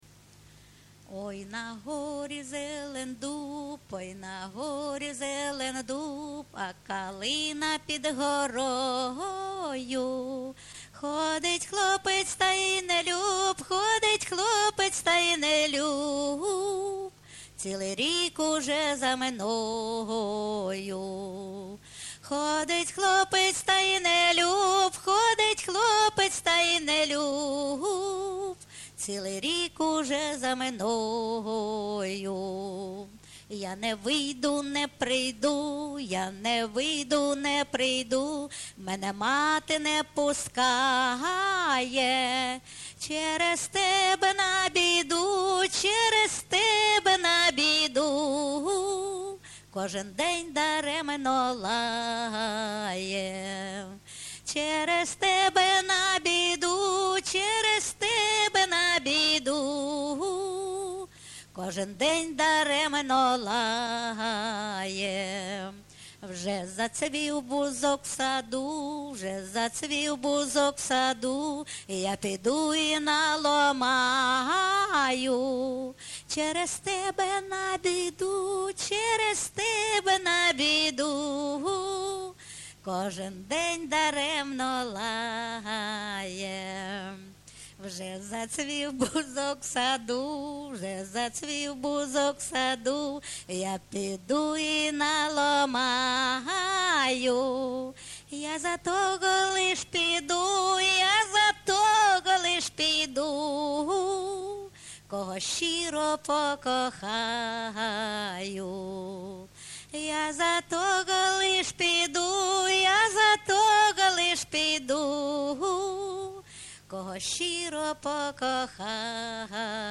ЖанрСучасні пісні та новотвори
Місце записус-ще Новодонецьке, Краматорський район, Донецька обл., Україна, Слобожанщина